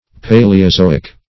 Paleozoic \Pa`le*o*zo"ic\ (p[=a]`l[-e]*[-o]*z[=o]"[i^]k), n.